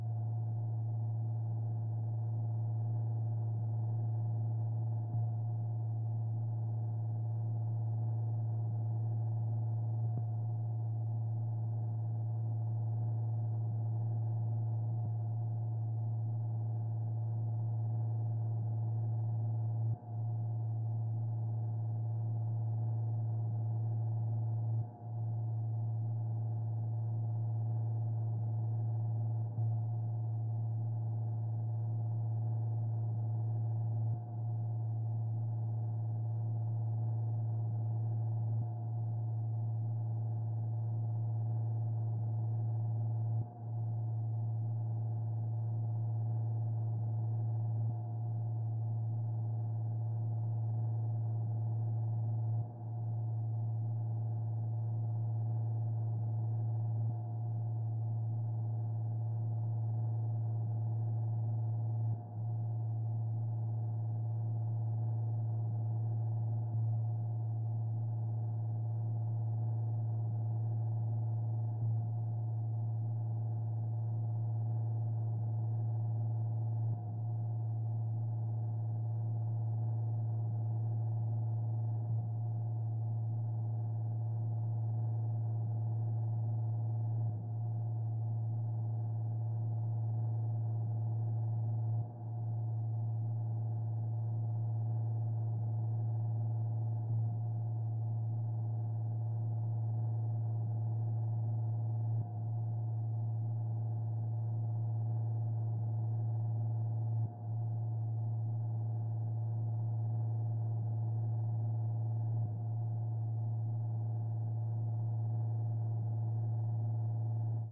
elevator.ogg